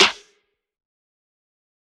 [ACD] - U.G.K. Snare.wav